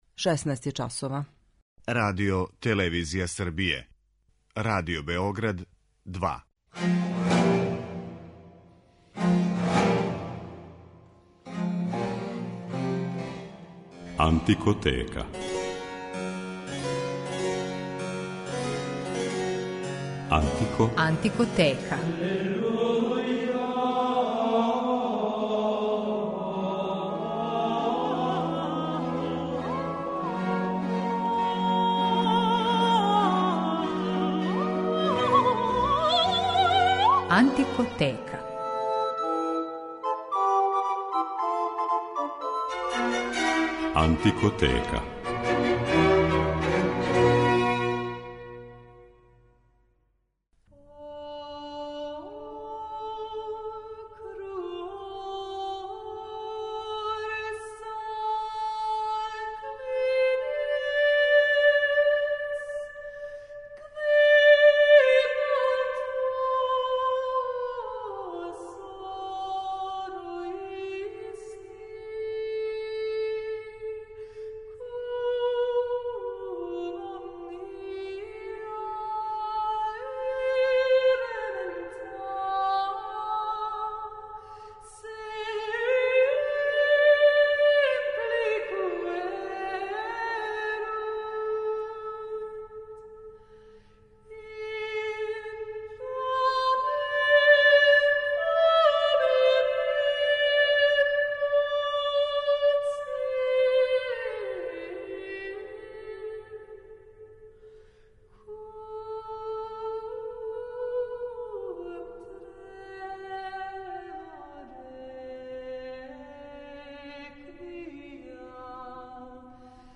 Хилдегард фон Бинген је посвећена данашња Антикотека, у којој ћете слушати одломке њеног мистерија Ordo Virtutum, антифоне, секвенце и лауде Светој Урсули.
води слушаоце у свет ране музике и прати делатност уметника специјализованих за ову област који свирају на инструментима из епохе или њиховим копијама.